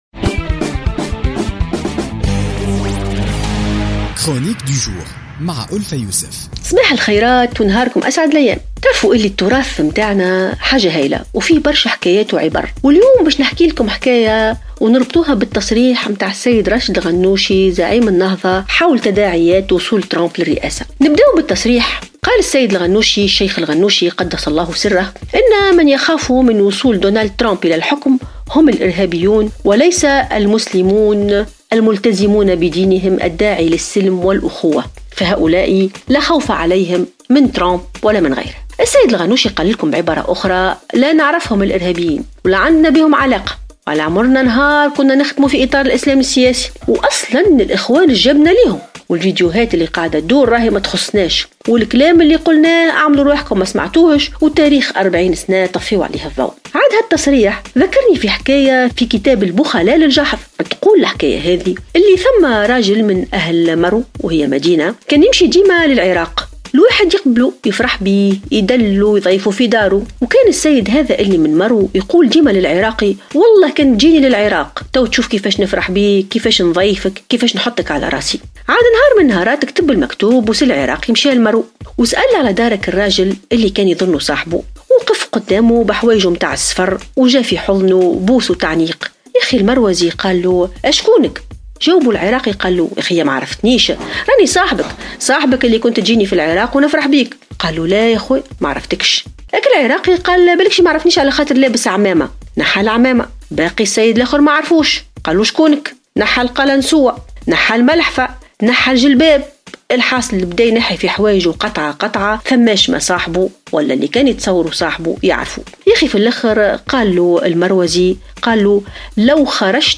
تطرقت الكاتبة ألفة يوسف افي افتتاحية اليوم الجمعة 27 جانفي 2017 إلى تصريحات راشد الغنوشي حول تداعيات وصول ترامب إلى السلطة الذي قال إن الإرهابيين هم من يجب أن يخافوا من وصوله إلى الحكم وليس المسلمون الملتزمون بدينهم .